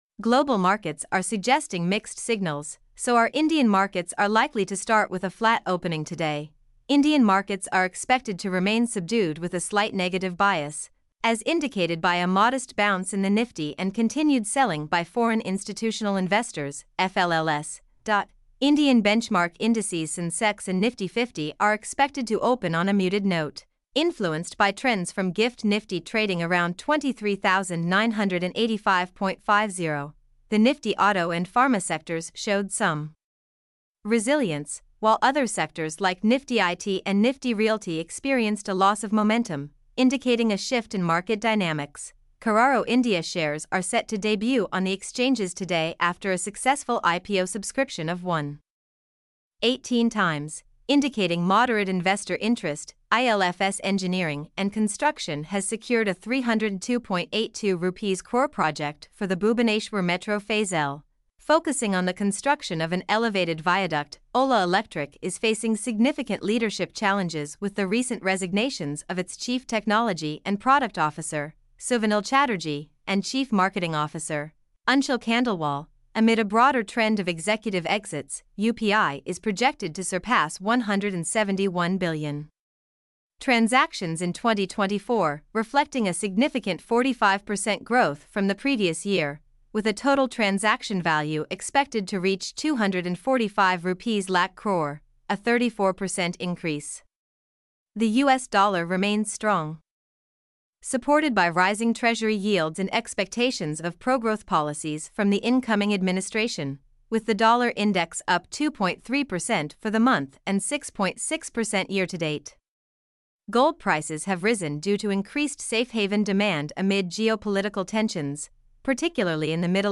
mp3-output-ttsfreedotcom-40.mp3